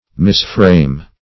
Misframe \Mis*frame"\, v. t. To frame wrongly.